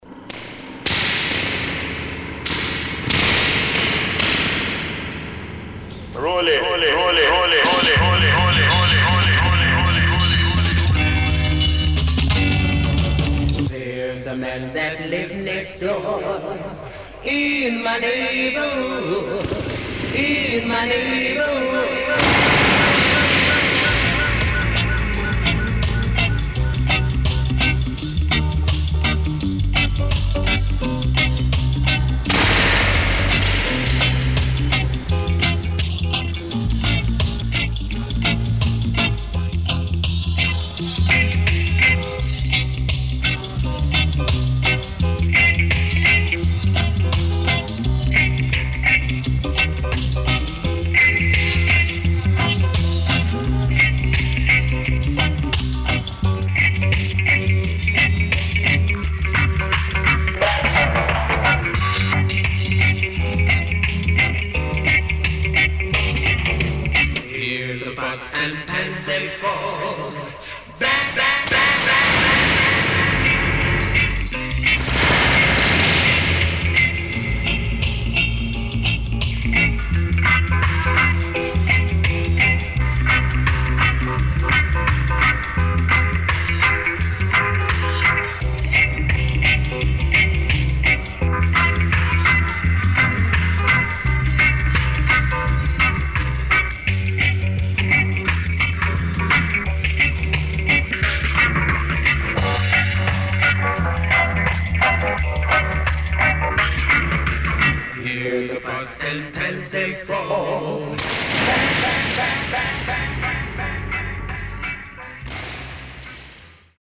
SIDE A:少しチリノイズ入りますが良好です。
SIDE B:少しチリノイズ入りますが良好です。